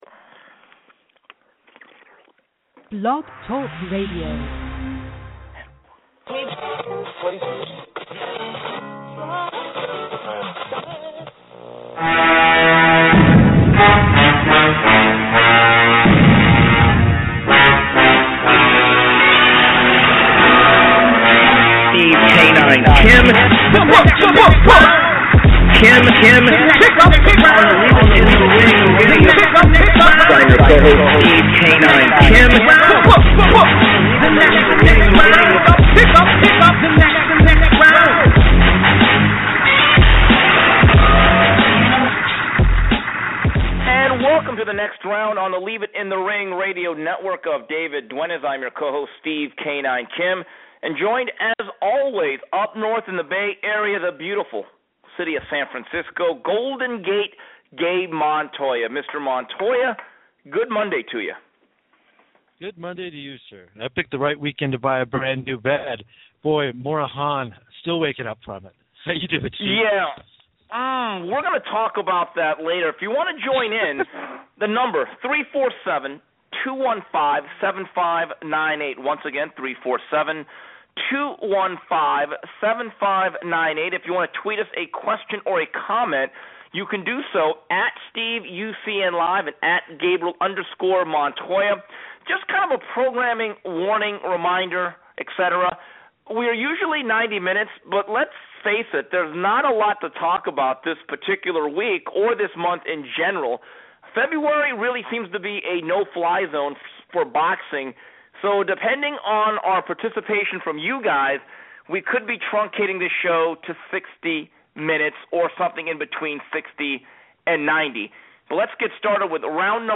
Plus, news, notes and your calls.